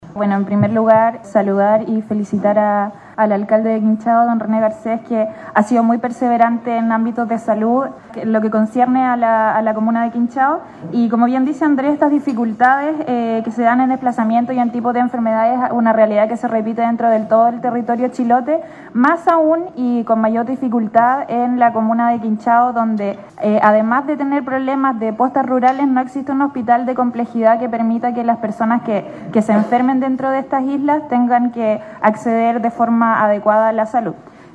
Para la Consejera Regional, Daniela Méndez, la asignación de recursos para la posta de Llingua es un logro importante para las localidades apartadas del archipiélago, que además no tienen en las cercanías, un hospital de mayor complejidad para la atención de los pacientes: